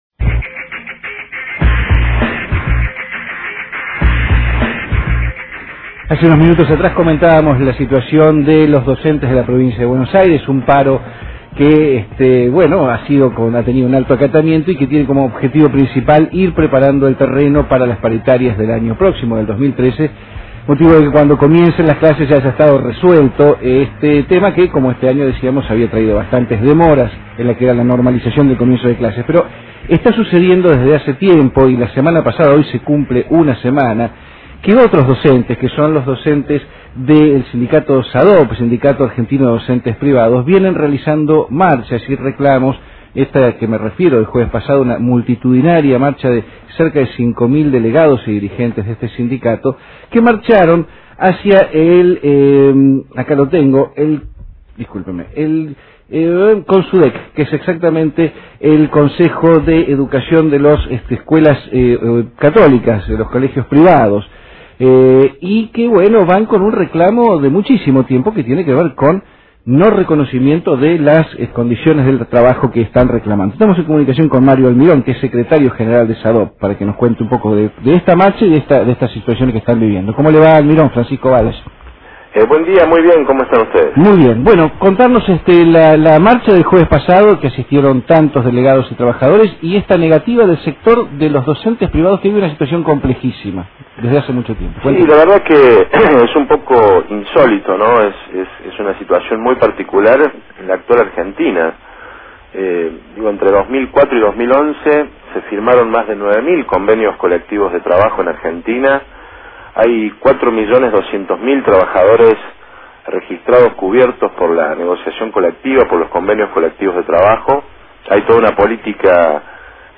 Aquí el audio de la entrevista.